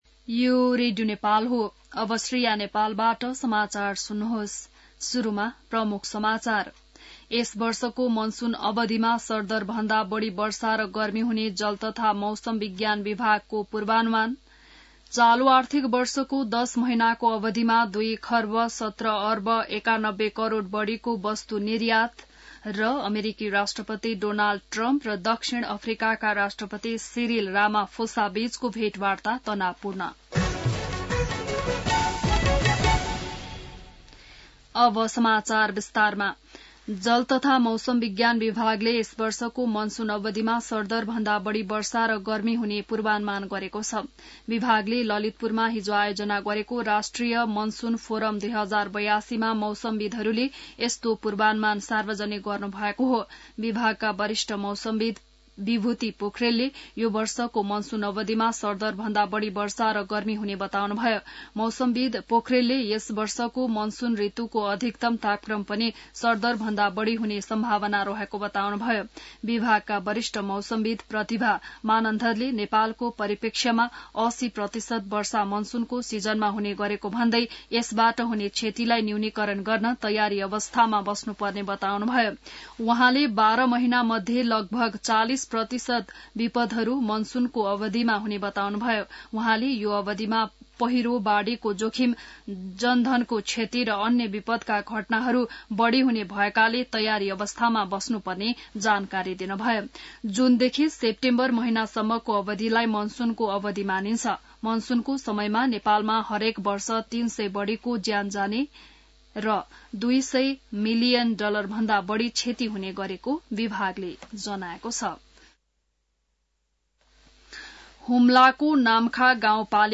बिहान ९ बजेको नेपाली समाचार : ८ जेठ , २०८२